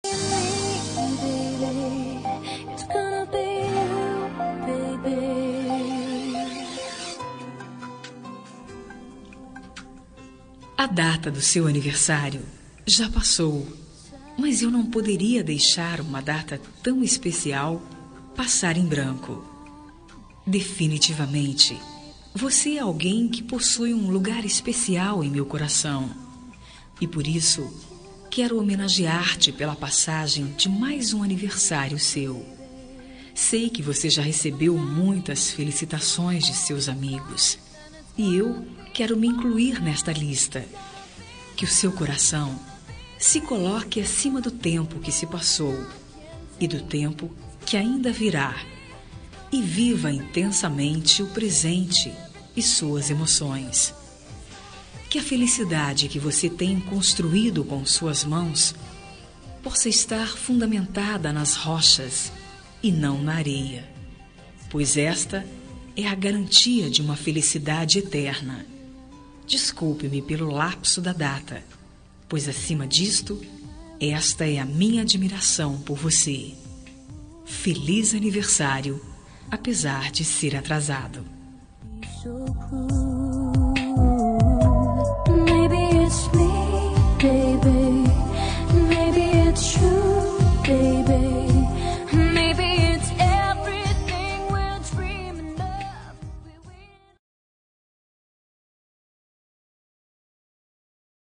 Aniversário Atrasado – Voz Feminina – Cód: 2492